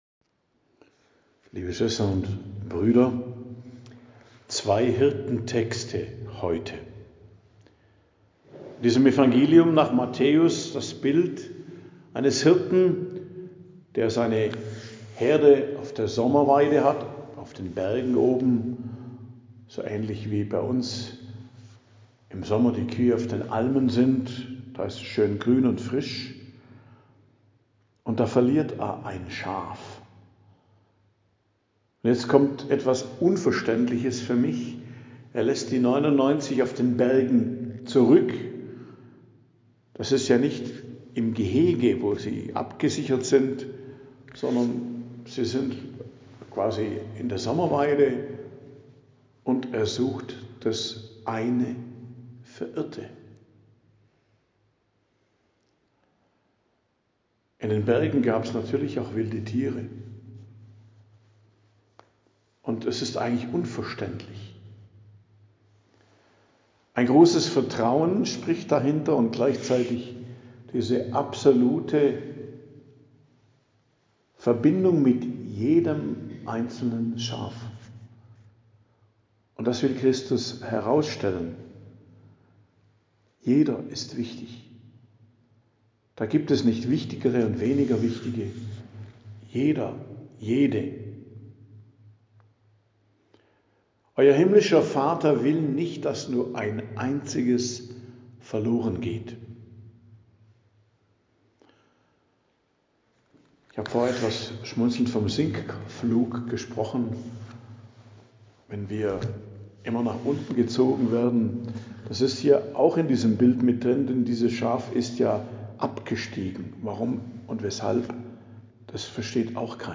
Predigt am Dienstag der 2. Woche im Advent, 9.12.2025